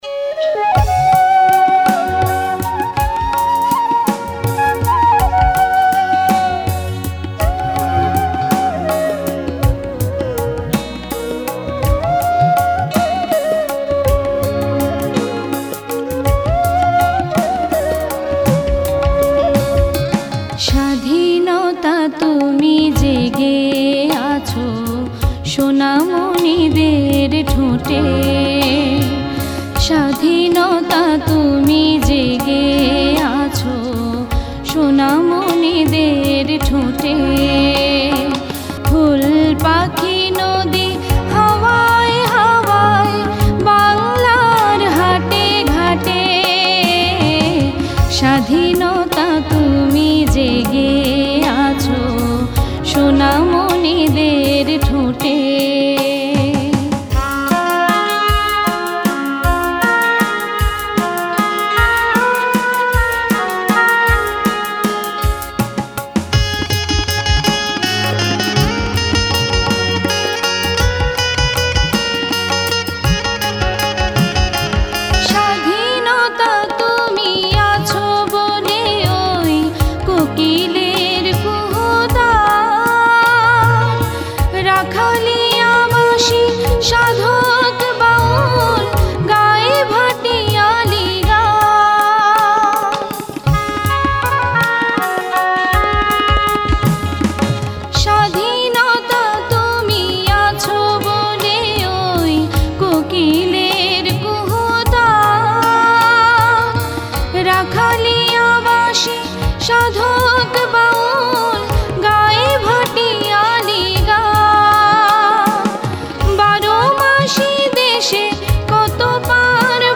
গান